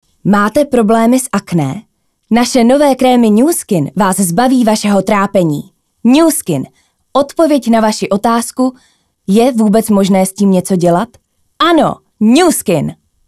ukázka Voice over:
ukázka reklama:
reklama-.mp3